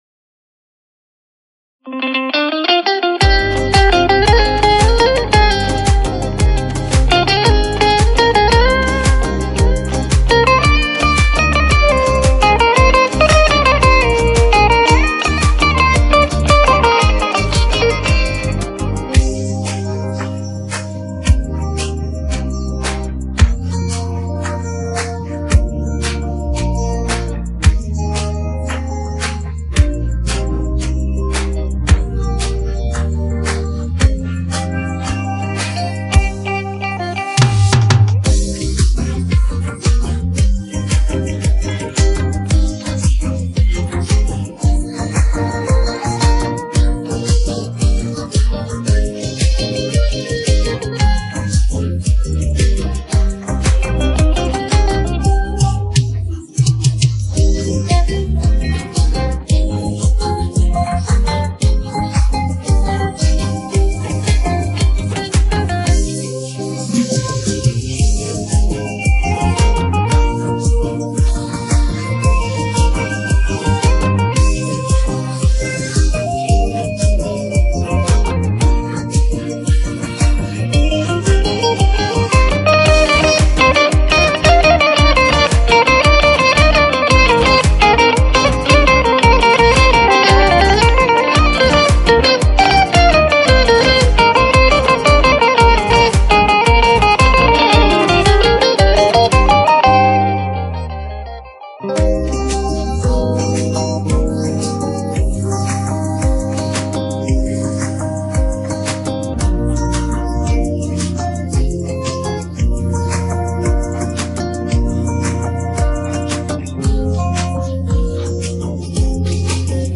با صدای دخنران